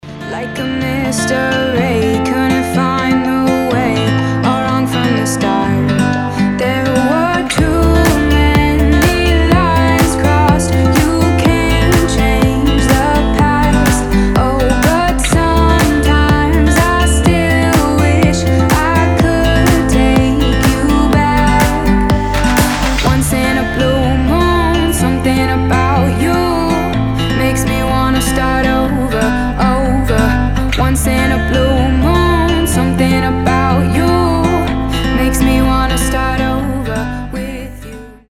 • Качество: 320, Stereo
гитара
deep house
приятные
красивый женский голос